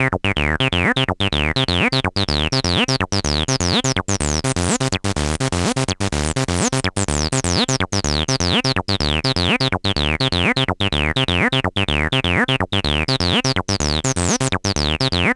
cch_acid_loop_phuture_125.wav